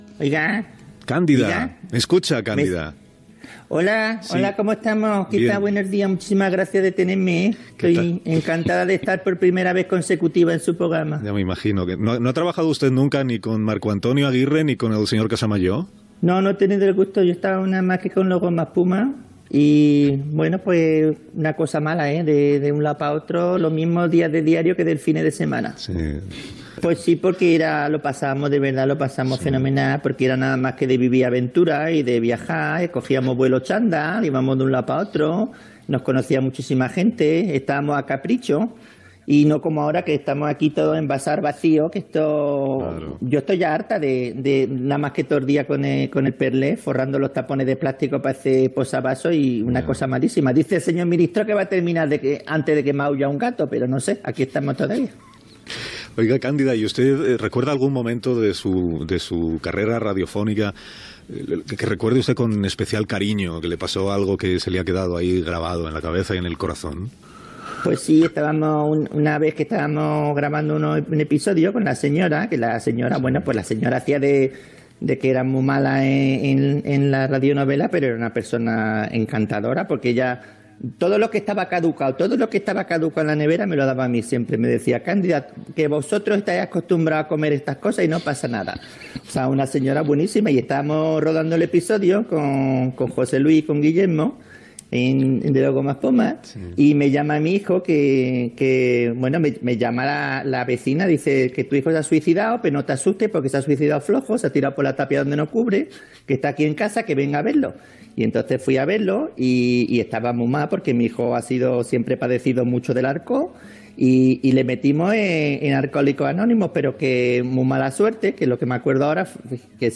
Fragment d'una entrevista al personatge "Cándida" de Gomaespuma.
Info-entreteniment